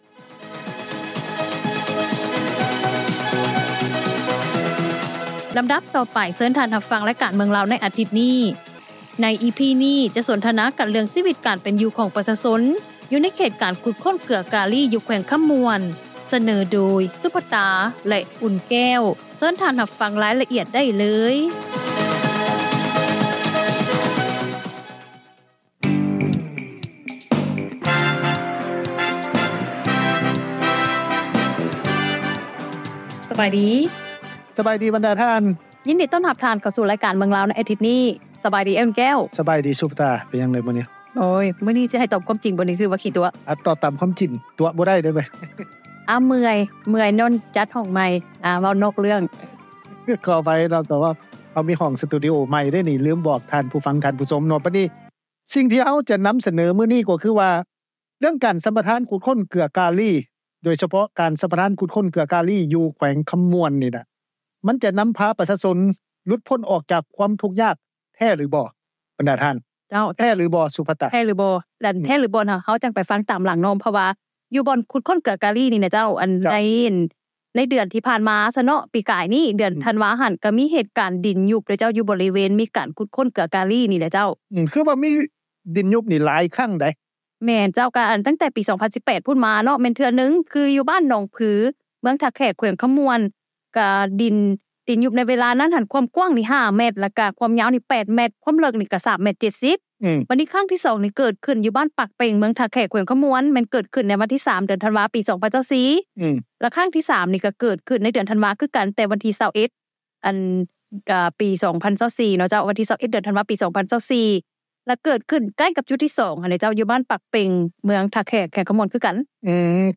ໃນລາຍການ ເມືອງລາວ ໃນອາທິດນີ້ EP-83 ສົນທະນາເລື່ອງ ຊີວິດການເປັນຢູ່ ຂອງປະຊາຊົນ ຢູ່ໃນເຂດການຂຸດຄົ້ນເກືອກາລີ ຢູ່ແຂວງຄໍາມ່ວນ.